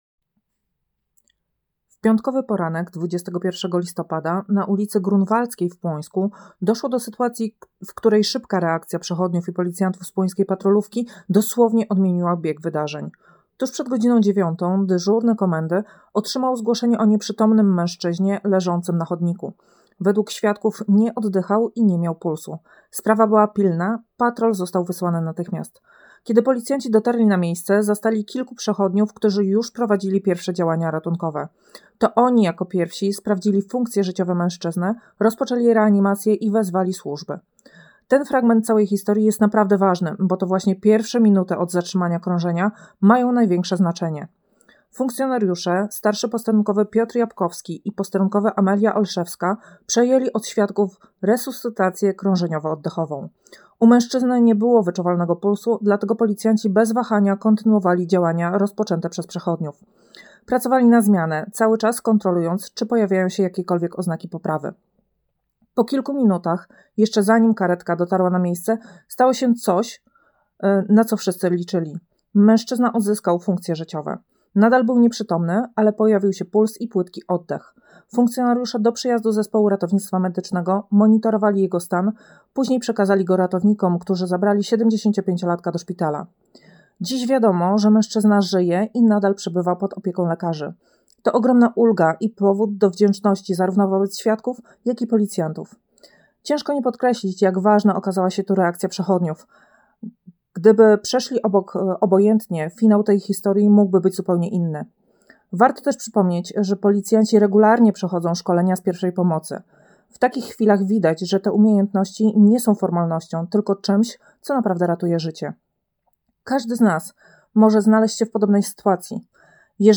Nagranie audio Wypowiedź nadkom.